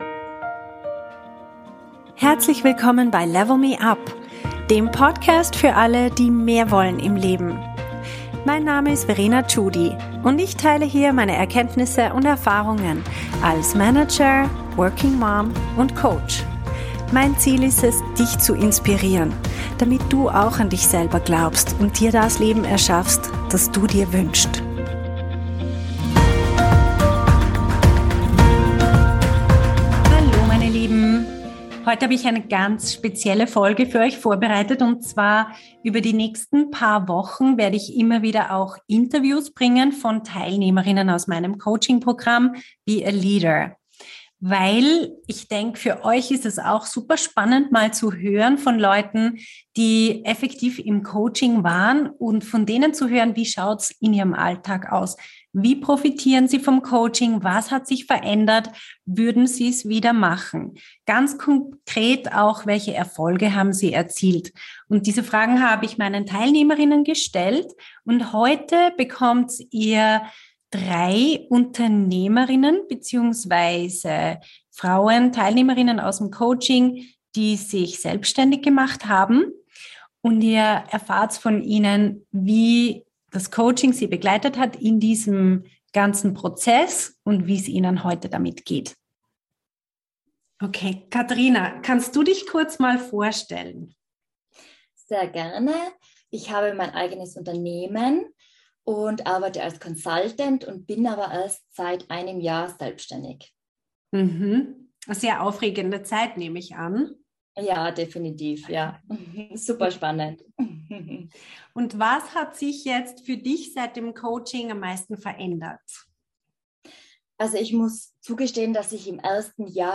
136_Interviews_Unternehmerinnen_final.mp3